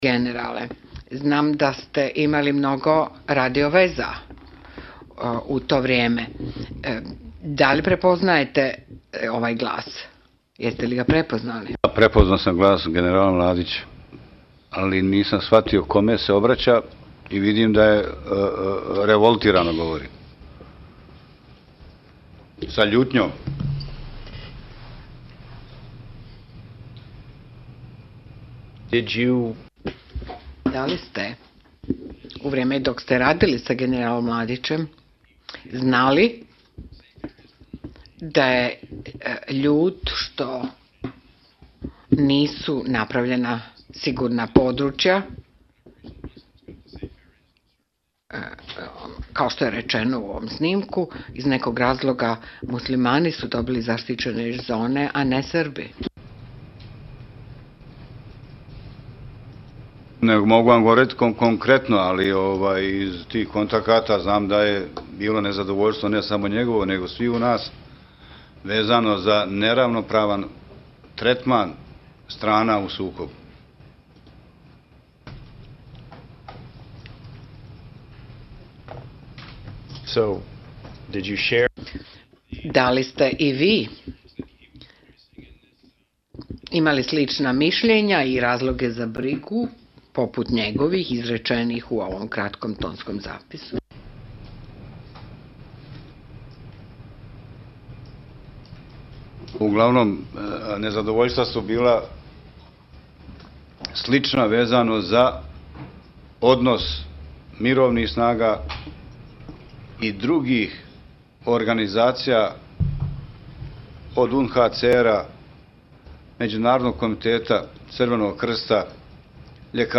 Dodatno ispitivanje i usmeni pozdrav umirovljenih generala VRS-a na kraju